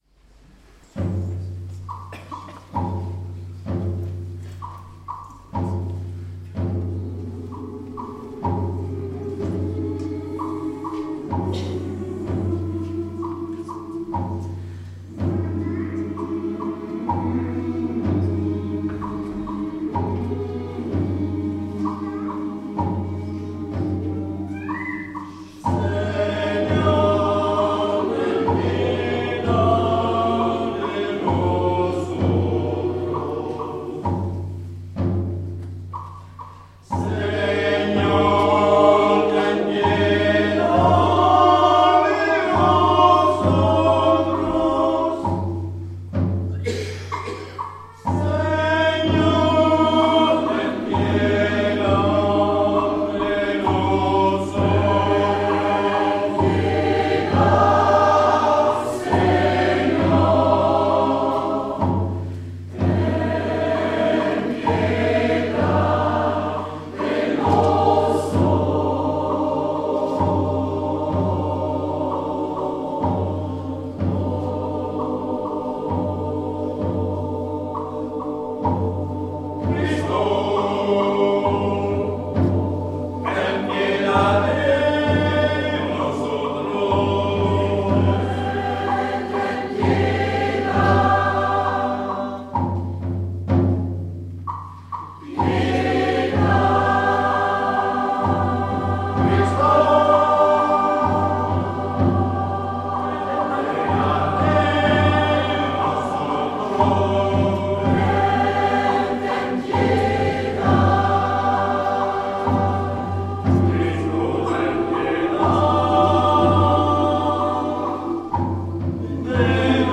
Extraits audios du concert du dimanche 12 mai 2013
Chorale HARMONIA de Saint Pavace Misa criolla - Kyrie A. Ramirez Harmonia St Pavace kyrie de missa criolla
17h00 : concert des trois chorales à l'Eglise Saint Bertrand du Mans :